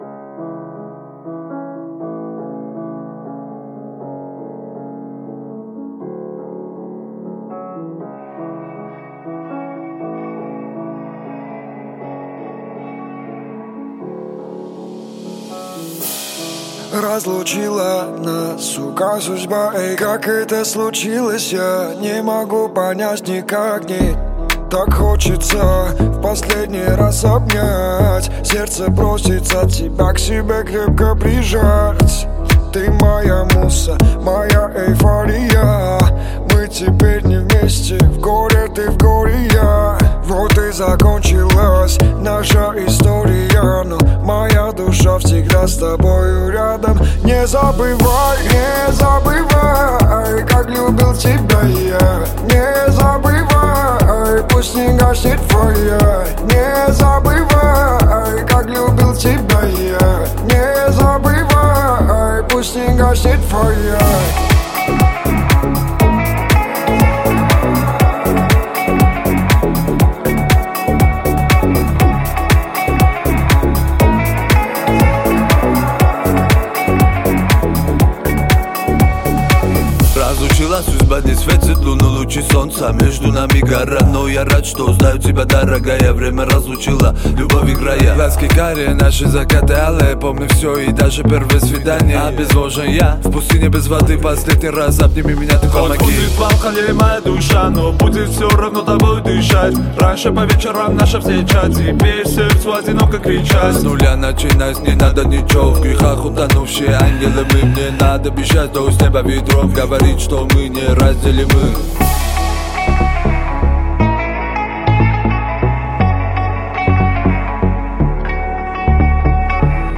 Русский рэп
Жанр: Русский рэп / В машину